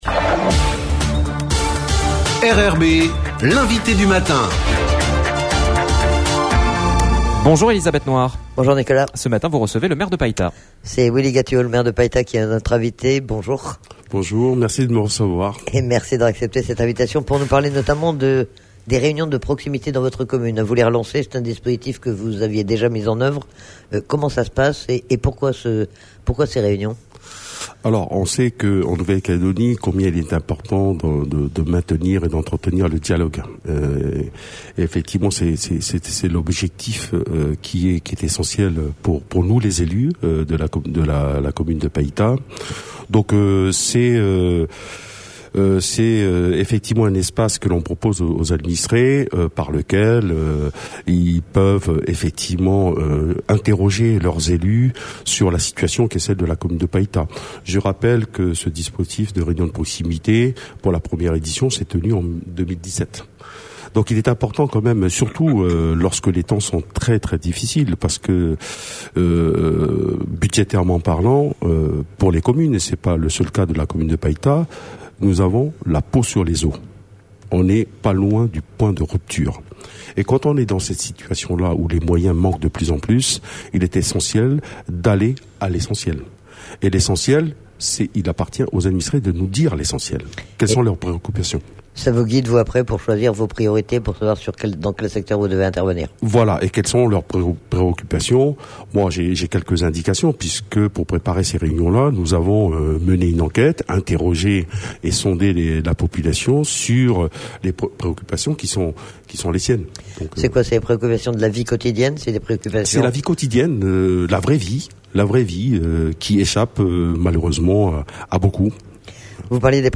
C'est le maire de Païta Willy Gatuhau qui est notre invité du matin. Il vient présenter les réunions de proximité qu'il organise dans sa commune. Il est également interrogé sur le récent séminaire des maires réuni par le haut-commissaire ou encore sur l'actualité politique calédonienne.